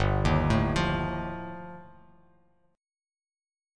G7sus2#5 chord